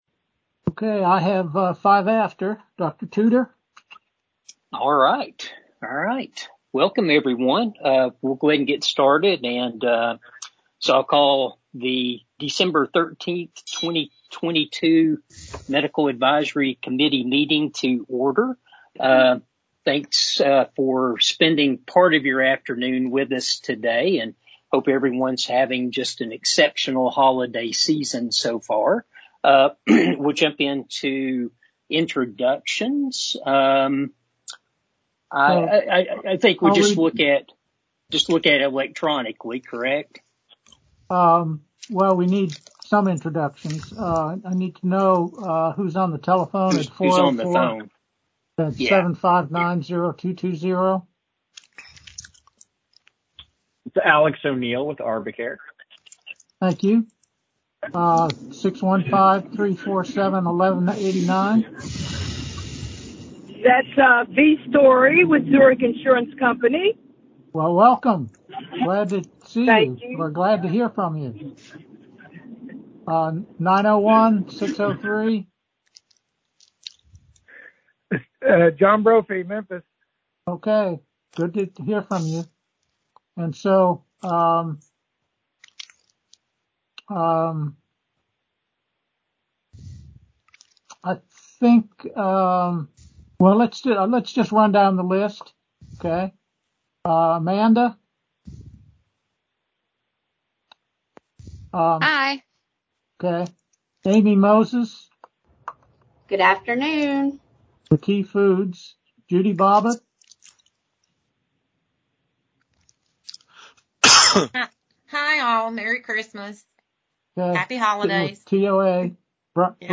Medical Advisory Committee Meeting
This meeting is open to the public and will be held at 220 French Landing Drive, Nashville, TN as well as virtually via Microsoft Teams.
Workers’ Compensation Large Conference Room (1st Floor, Side B) 220 French Landing Drive Nashville, TN or via Microsoft Teams